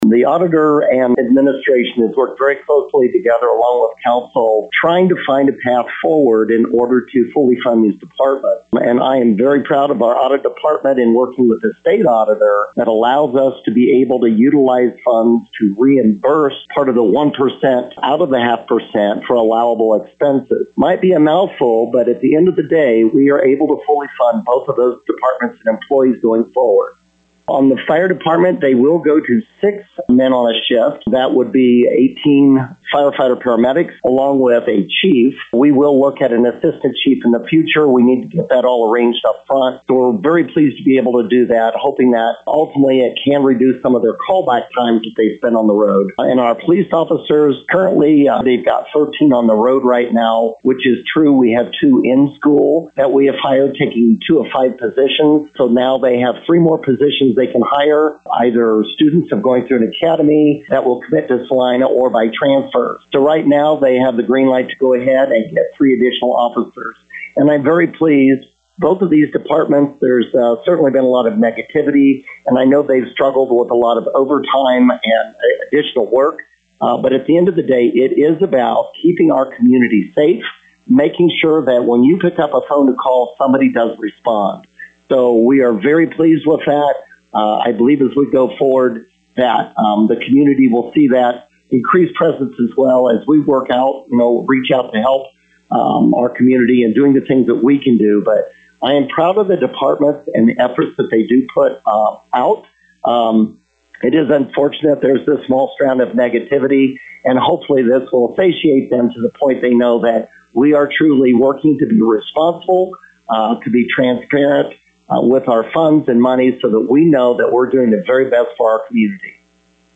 Local News
Mayor Jeff Hazel Comments: